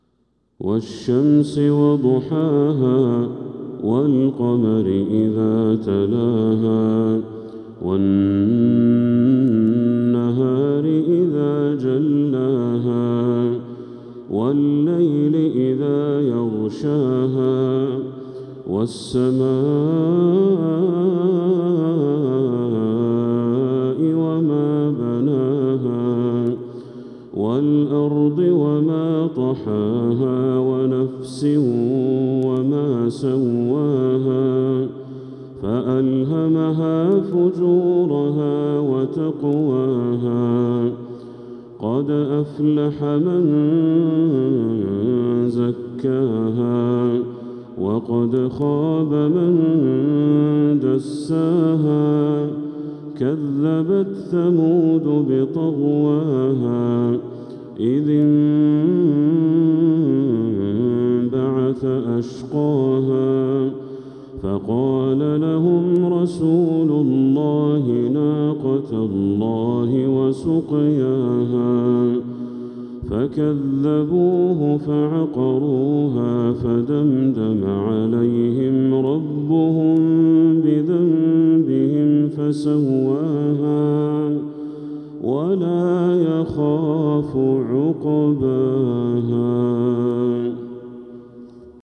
سورة الشمس كاملة | ذو الحجة 1446هـ > السور المكتملة للشيخ بدر التركي من الحرم المكي 🕋 > السور المكتملة 🕋 > المزيد - تلاوات الحرمين